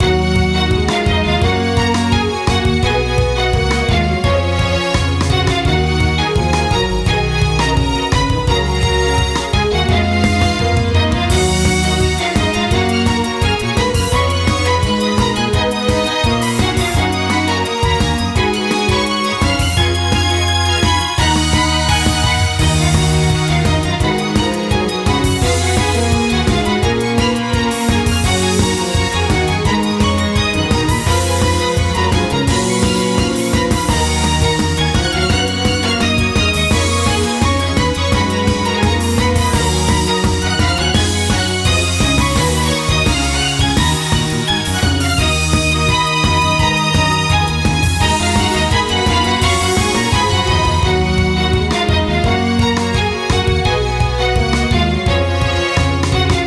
RPGやアクションゲームなどの通常戦闘シーンを想定して制作した、テンポ感のある戦闘用BGMです。
ジャンル： ゲーム音楽／バトルBGM／ファンタジー／アクション
雰囲気： テンポ感／疾走感／緊張感／軽快／前向き